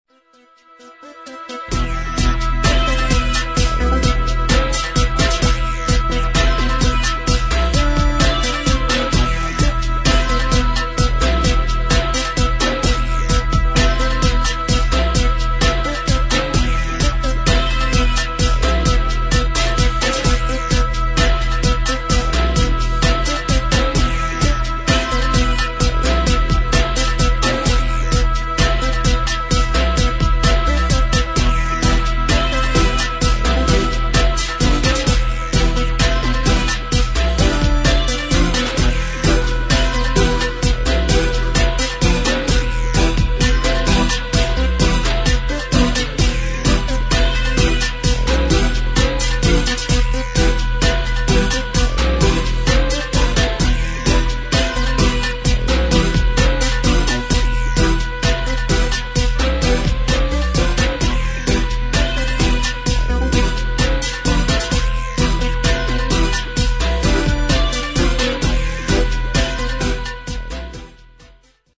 [ DUB / DUBSTEP ]